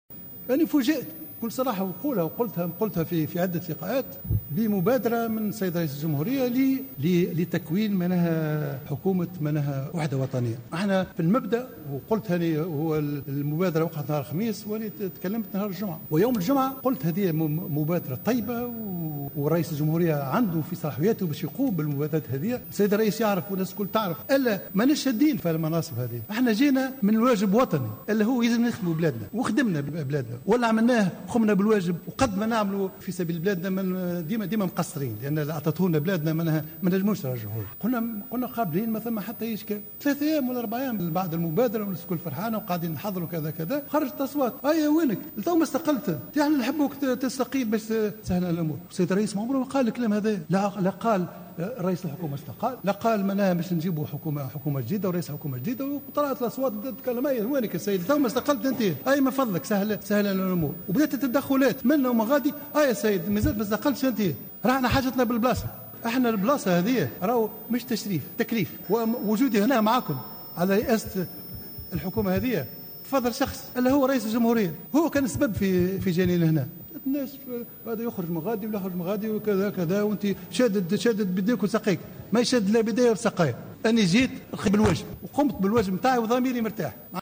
قال رئيس الحكومة الحبيب الصيد اليوم خلال جلسة منح الثقة للحكومة من عدمه إن قبوله بتولي منصب رئاسة الحكومة كان من باب الواجب الوطني وإنه لا يطمح في المناصب.